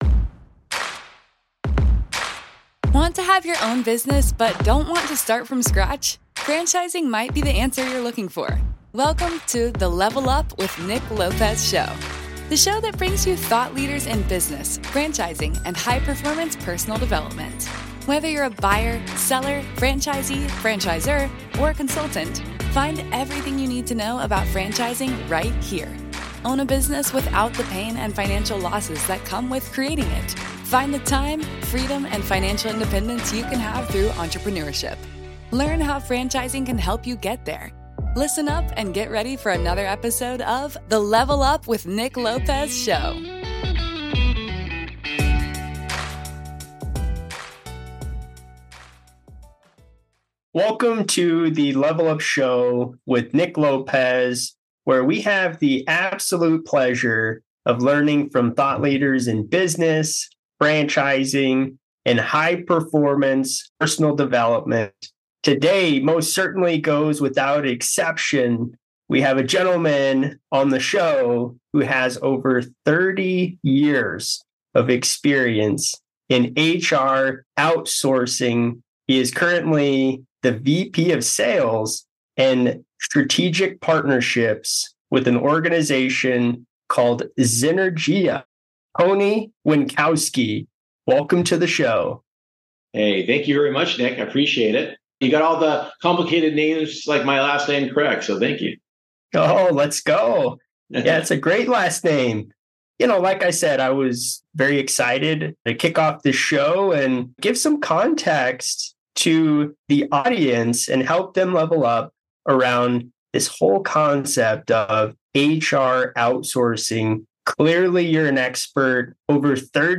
To master the art of franchising success, you must first unlock the potential of human capital. In this week's episode of The Level Up Show, get ready for an enlightening conversation